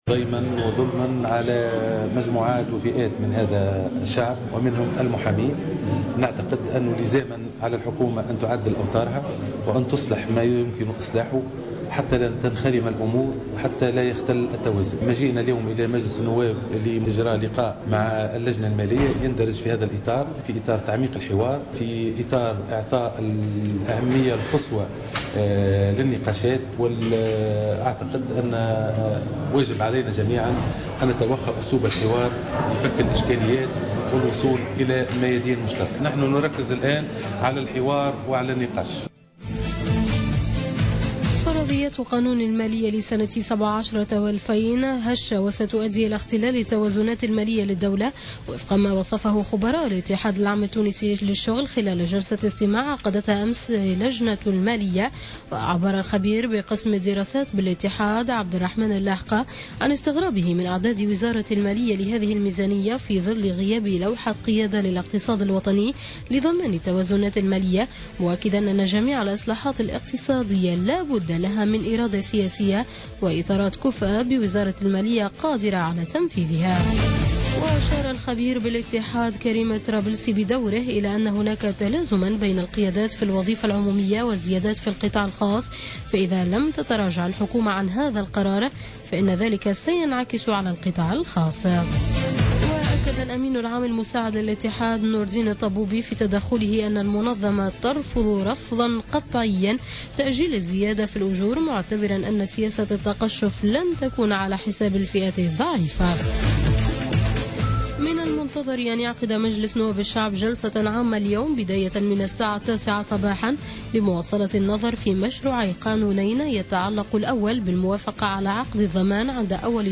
نشرة أخبار منتصف الليل ليوم الاربعاء 2 نوفمبر 2016